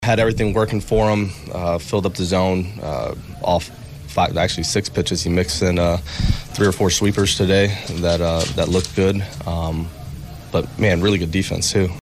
STL Cardinals manager Oliver Marmol says starter Miles Mikolas was sharp on the mound.